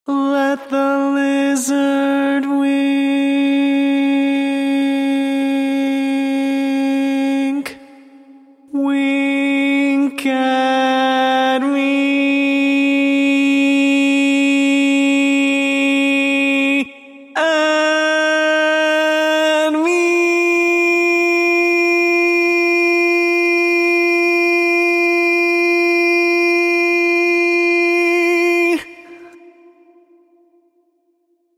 Key written in: C# Major
Type: Barbershop
Each recording below is single part only.